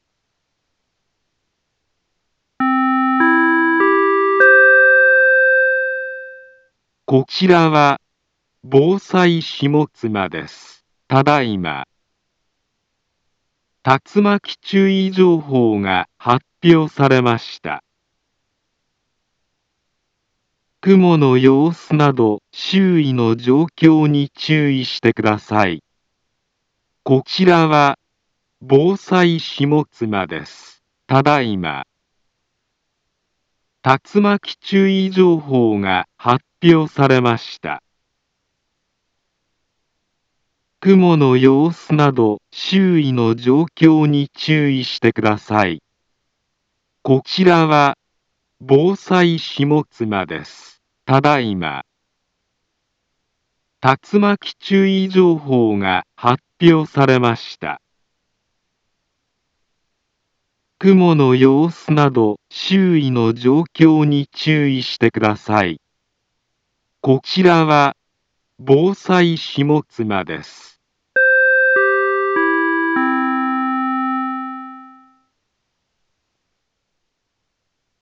Back Home Ｊアラート情報 音声放送 再生 災害情報 カテゴリ：J-ALERT 登録日時：2025-09-03 16:34:37 インフォメーション：茨城県南部は、竜巻などの激しい突風が発生しやすい気象状況になっています。